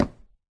Sound / Minecraft / dig / wood4